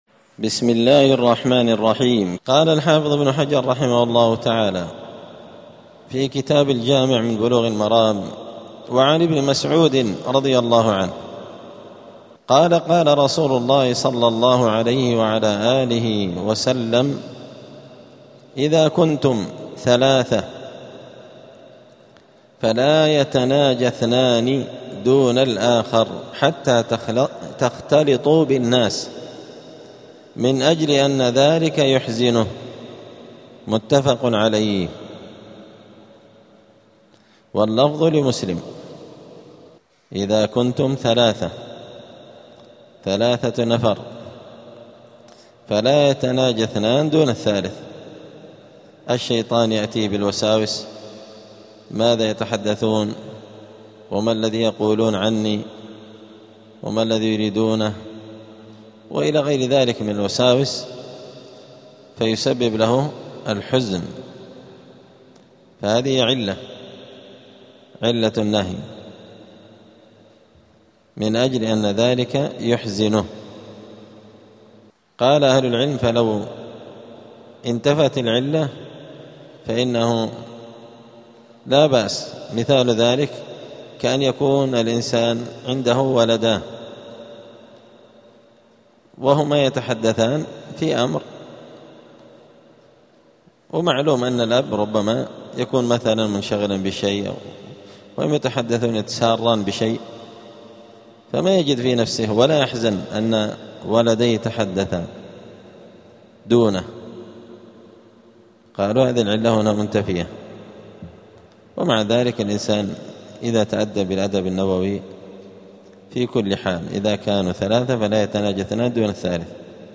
*الدرس الرابع (4) {باب ﻻ ﻳﺘﻨﺎﺟﻰ اﺛﻨﺎﻥ ﺩﻭﻥ اﻟﺜﺎﻟﺚ}*
دار الحديث السلفية بمسجد الفرقان قشن المهرة اليمن